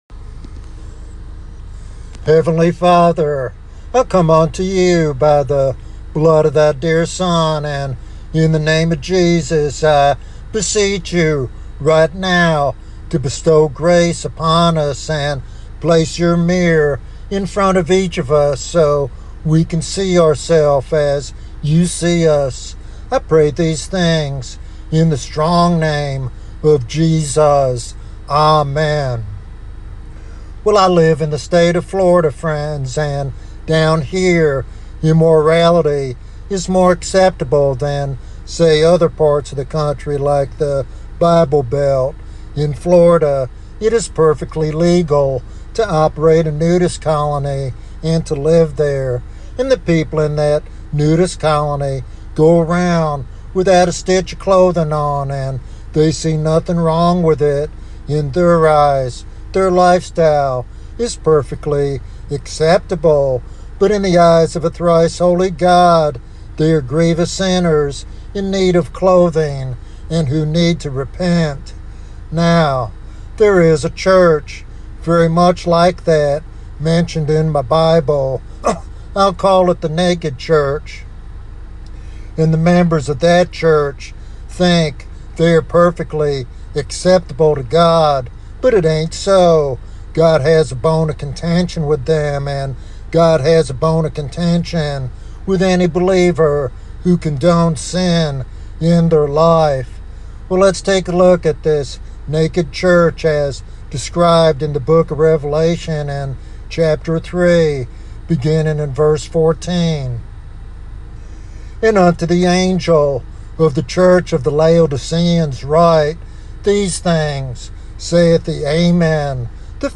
Drawing from Revelation 3, he reveals how complacency and unconfessed sin leave the church vulnerable and separated from Christ’s fellowship.
Sermon Outline